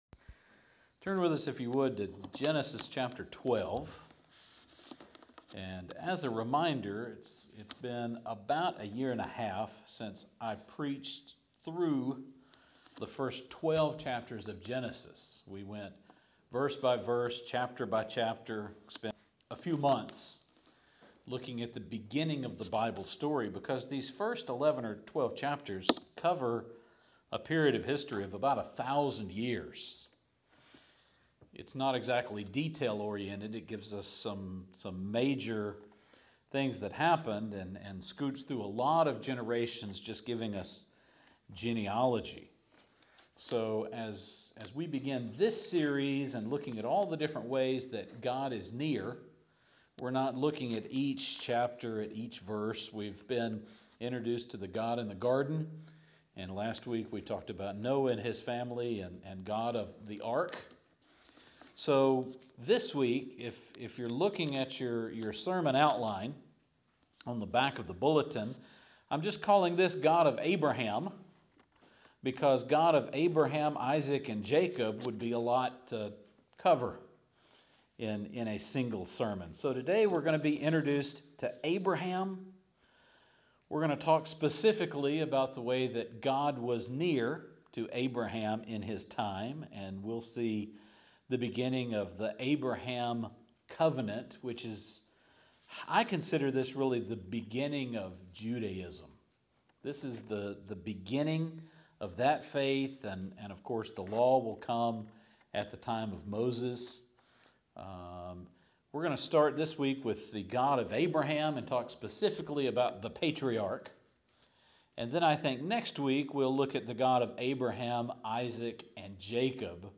The God of Abraham, Isaac and Jacob would be a bit much for a single sermon. Today we will meet Abraham, actually just Abram at this point, and consider the covenant God made with him.